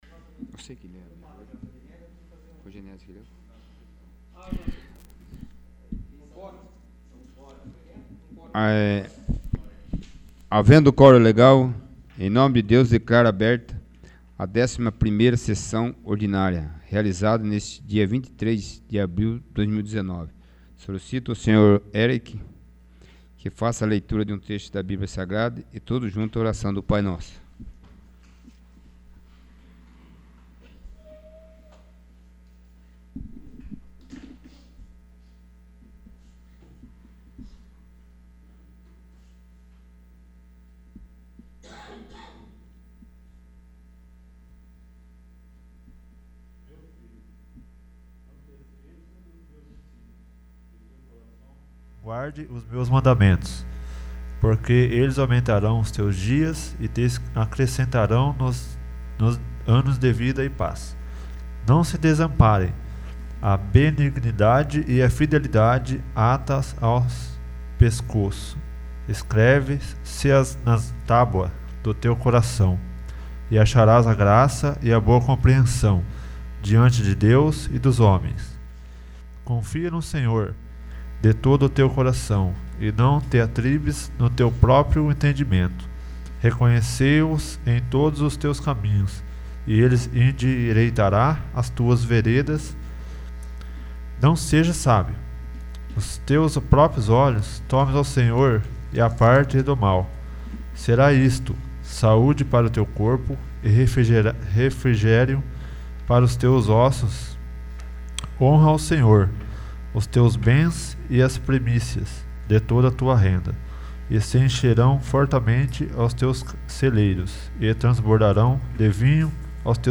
11º. Sessão Ordinária